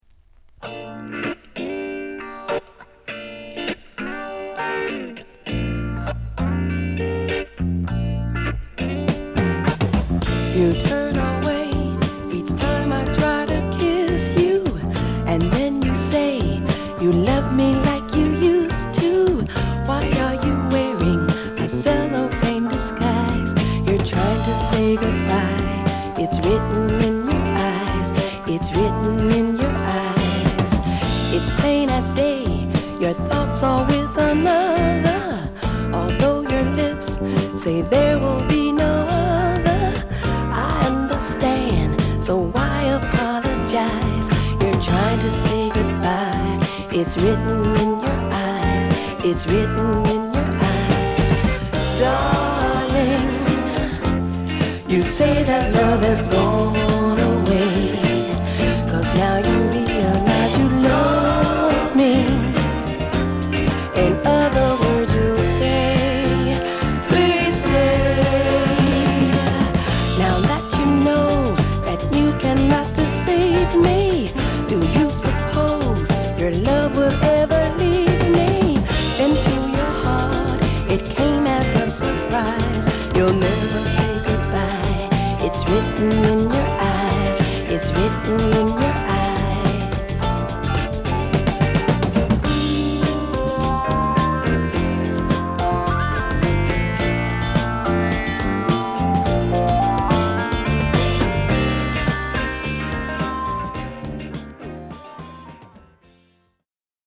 録音の荒らさはAll Platinum対抗馬！